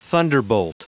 Prononciation du mot thunderbolt en anglais (fichier audio)